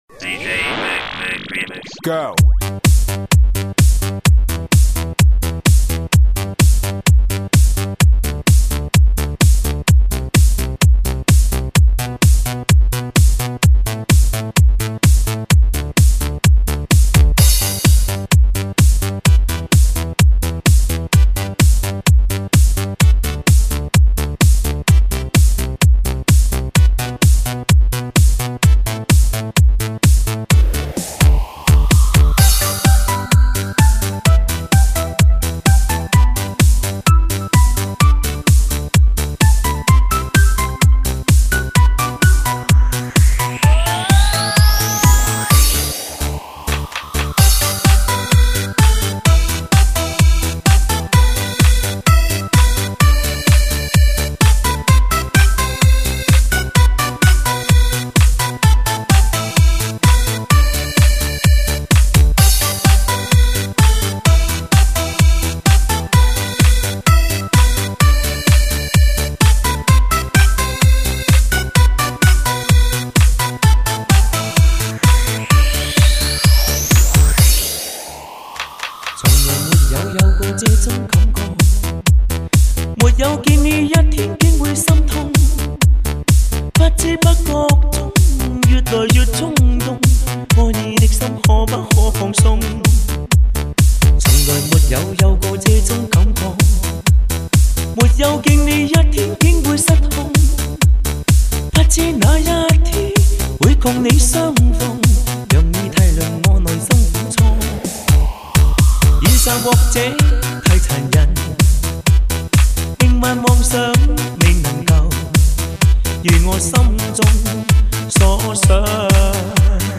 强力DJ慢摇舞曲 引爆迪厅音乐嗨潮
迪厅必备 劲暴之王